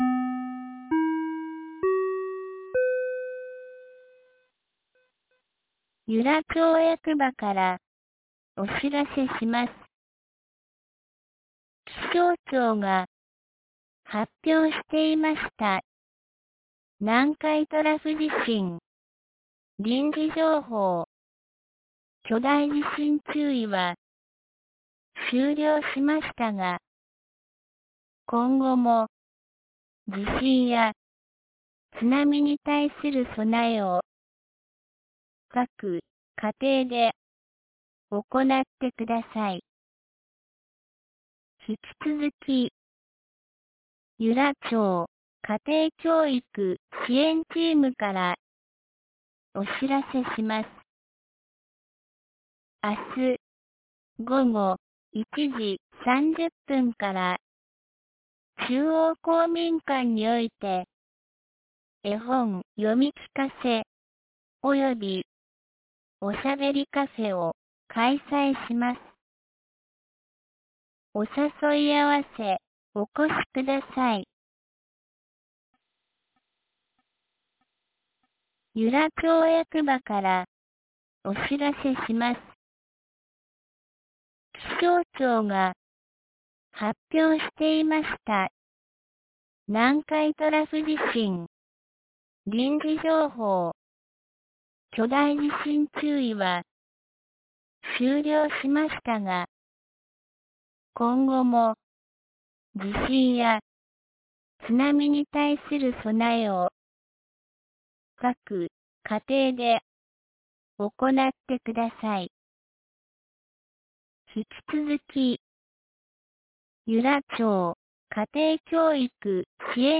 2024年08月16日 17時13分に、由良町から全地区へ放送がありました。